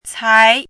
“材”读音
cái
材字注音：ㄘㄞˊ
国际音标：tsʰĄi˧˥
cái.mp3